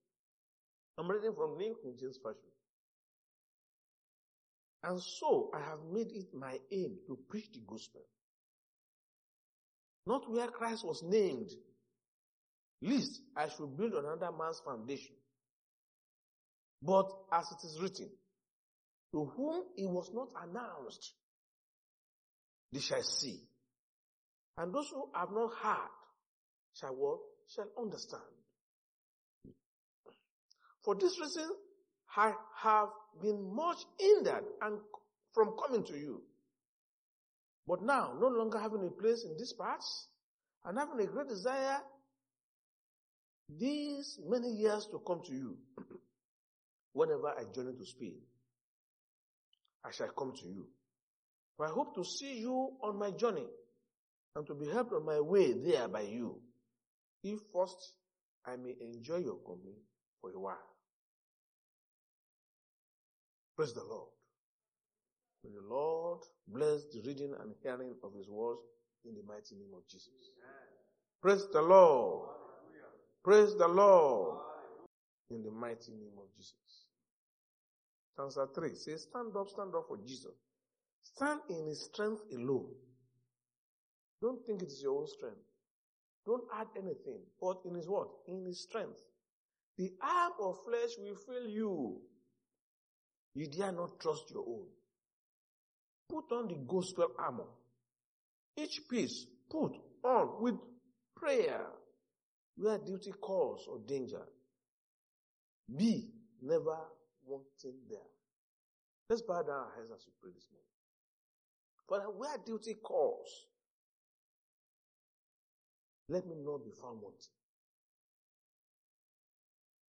Sunday Sermon: Go Into The World
Service Type: Sunday Church Service